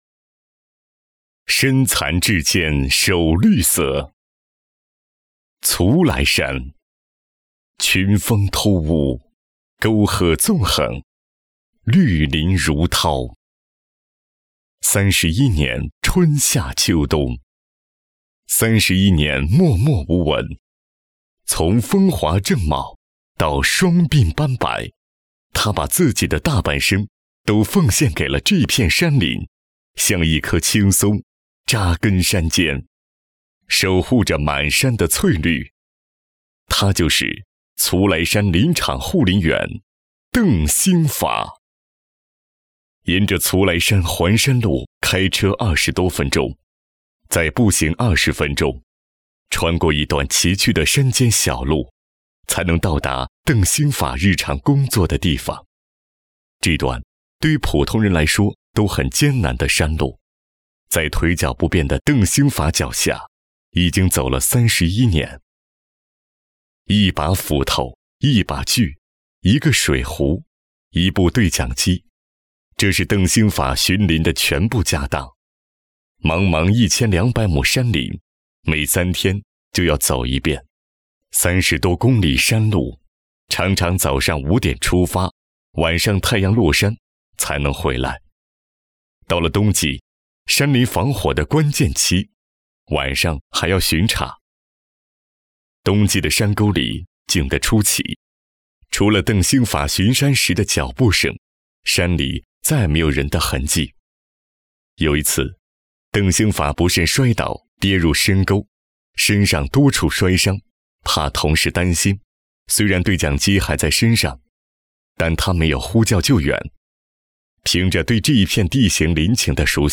162男-大气恢宏
擅长：专题片 广告
特点：大气浑厚 稳重磁性 激情力度 成熟厚重
风格:浑厚配音